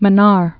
(mə-när), Gulf of